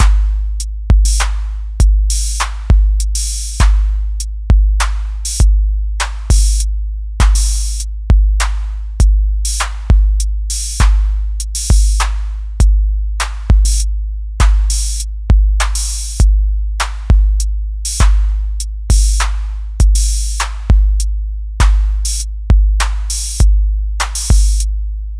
If you solo any one of these hits it’s a boring none changing constant pulse. No more complicated then clock tic.
This rhythm takes 11 bars & 3 beats to fully repeat/loop.
Beat/pulse count
• hat = 4
• kick = 6
• open hat = 7
• clap = 8